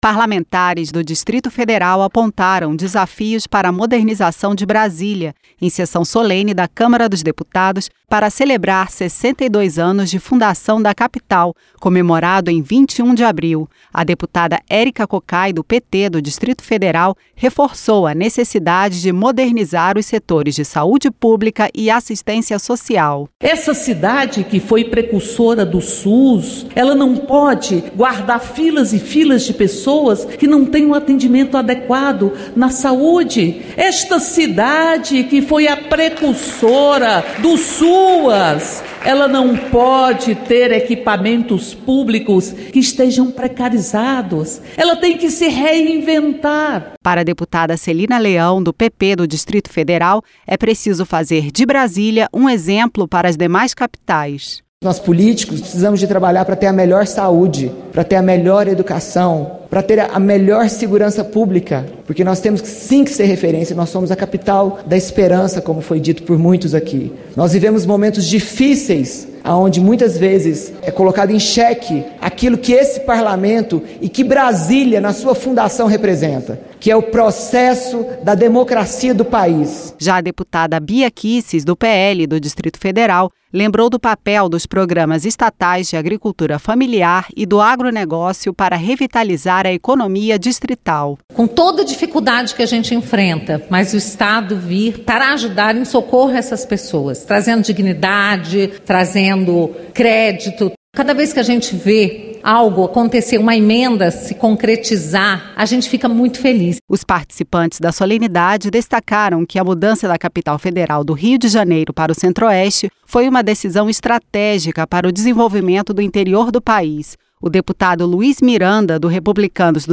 Sessão solene na Câmara celebrou os 62 anos da capital do País
• Áudio da matéria